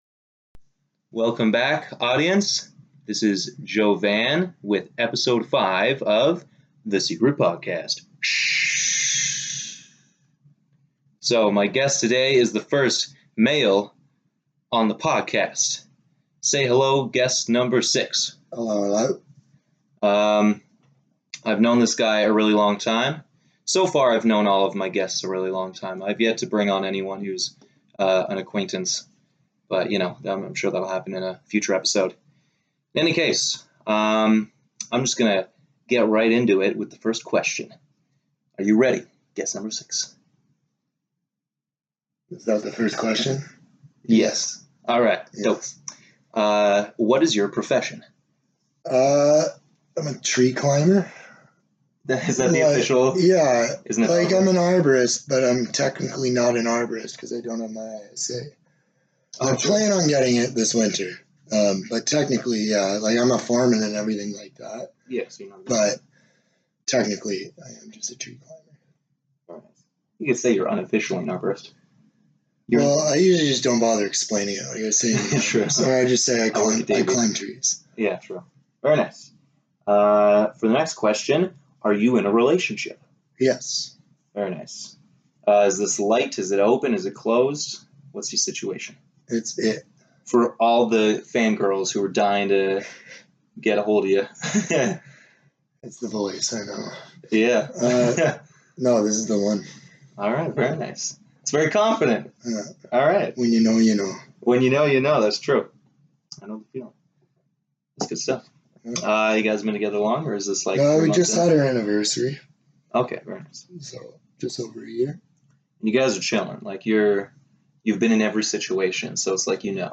In this episode I interview the first guy.